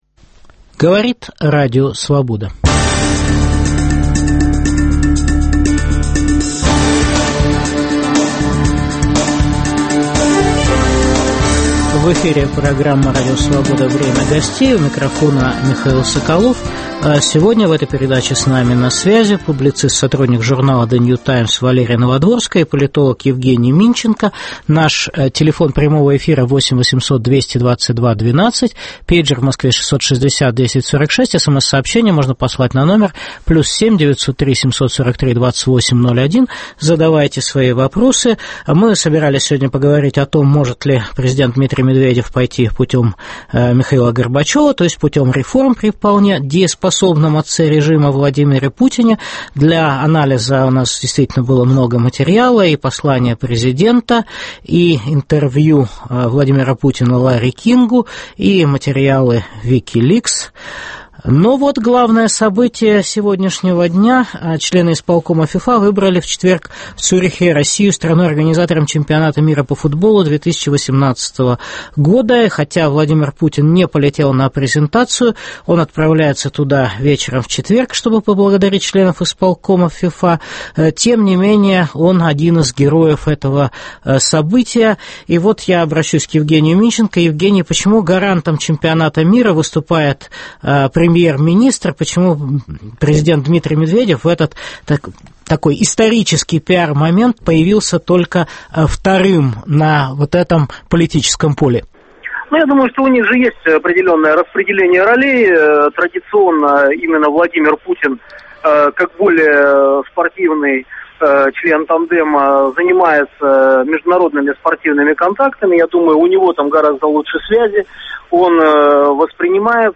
Может ли Дмитрий Медведев пойти путем Михаила Горбачева? Гости – публицист, сотрудник журнал "The New Times" Валерия Новодворская и политолог Евгений Минченко.